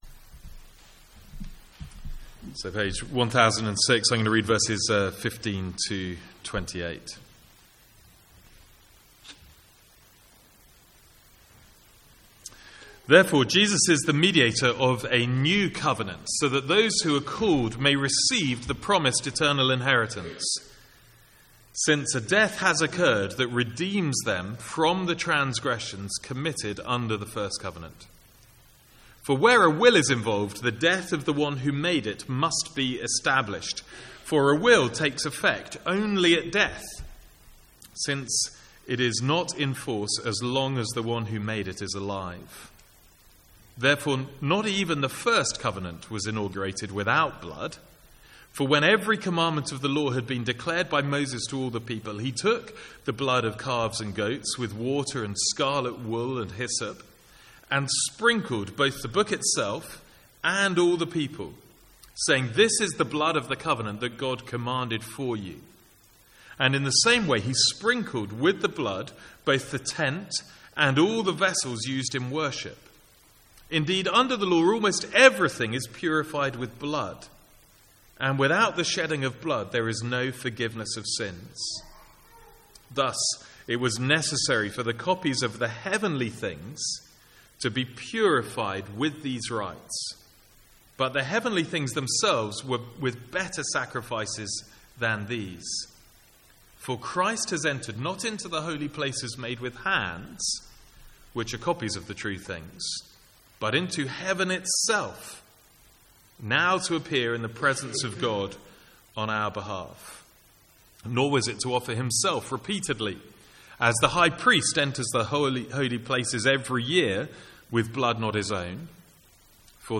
Sermons | St Andrews Free Church
From the Sunday evening series in Hebrews.